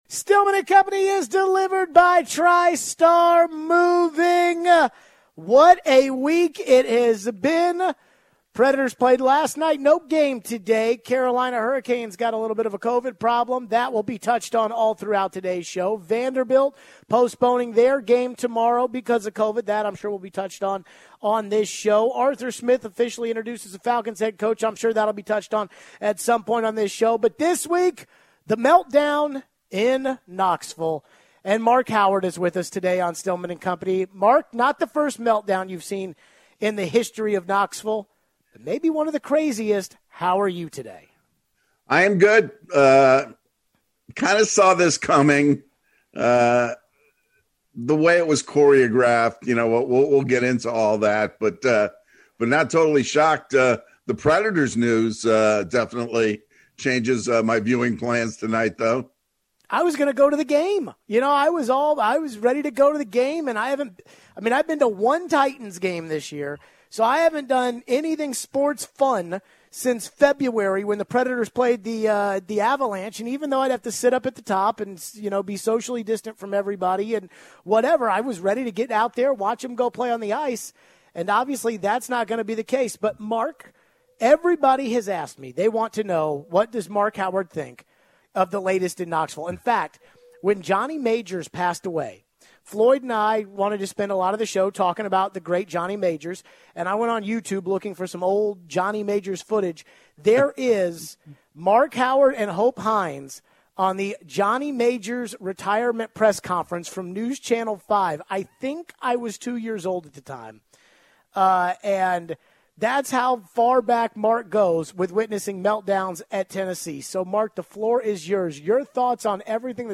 Plus we take your calls and texts.